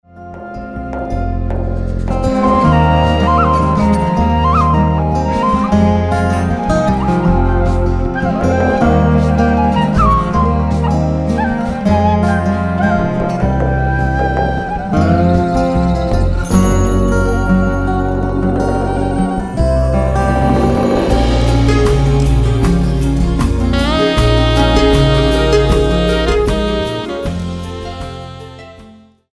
besides various harps